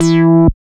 69.09 BASS.wav